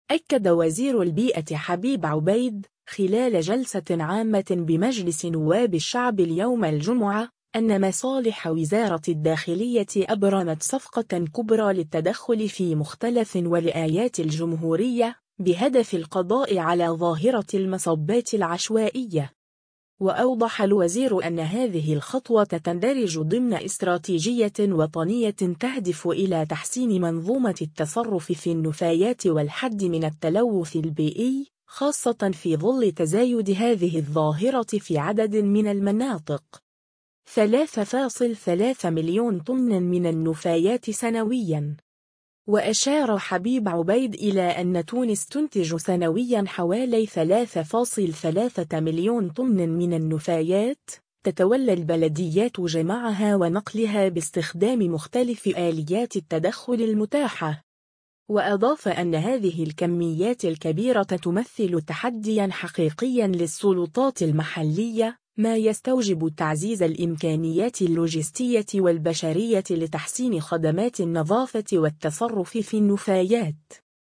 أكد وزير البيئة حبيب عبيد، خلال جلسة عامة بمجلس نواب الشعب اليوم الجمعة، أن مصالح وزارة الداخلية أبرمت صفقة كبرى للتدخل في مختلف ولايات الجمهورية، بهدف القضاء على ظاهرة المصبات العشوائية.